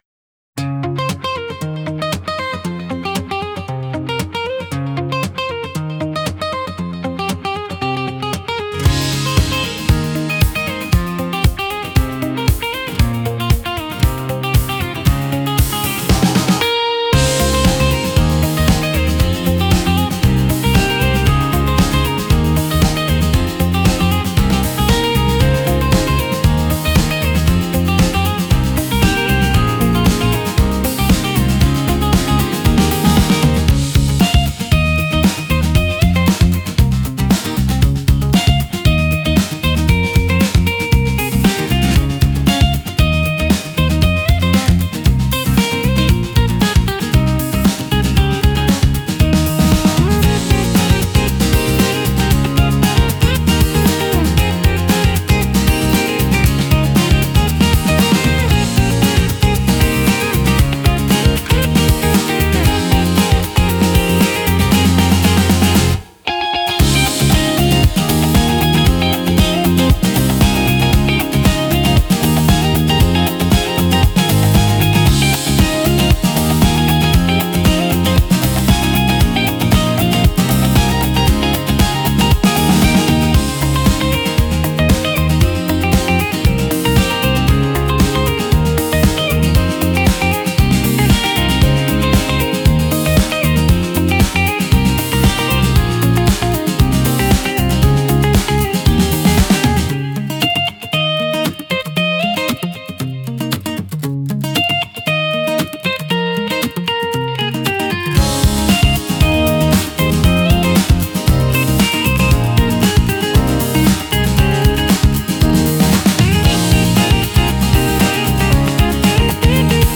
かっこいい エモい ロック